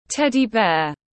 Gấu bông Teddy tiếng anh gọi là Teddy Bear, phiên âm tiếng anh đọc là /ˈted•i ˌbeər/